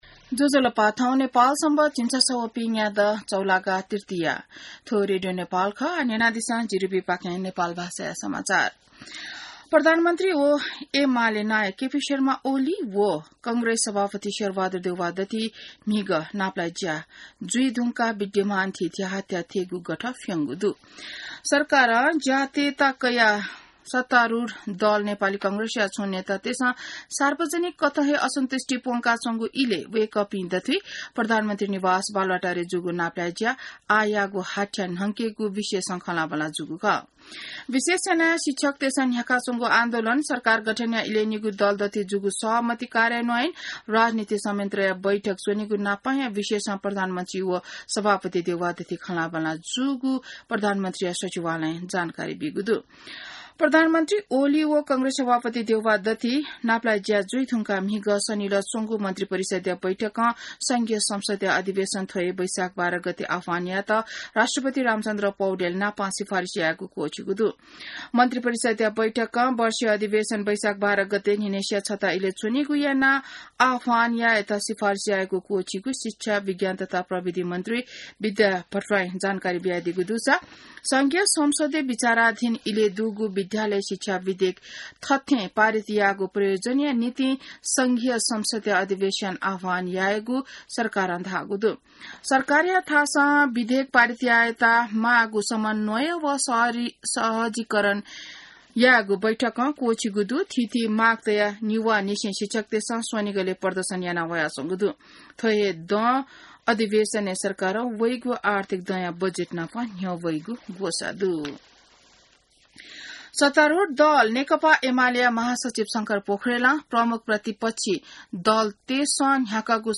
नेपाल भाषामा समाचार : ३ वैशाख , २०८२